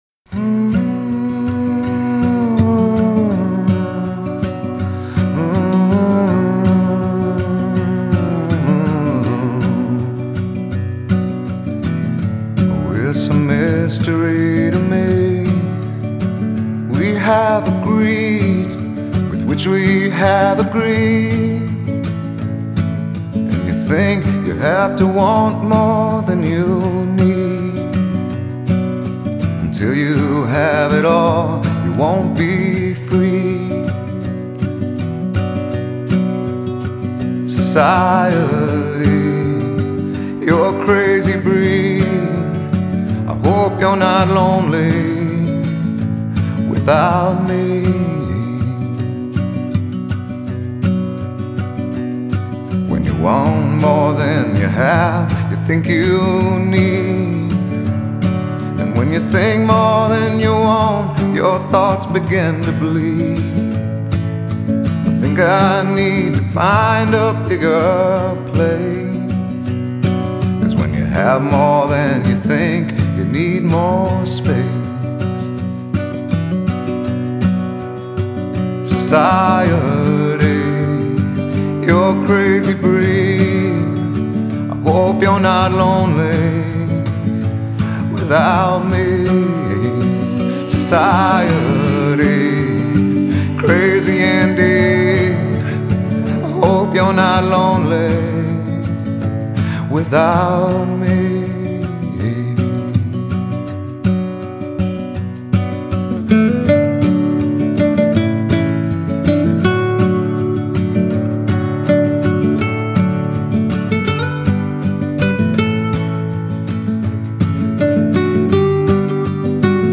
Kind of mellow dramatic, but click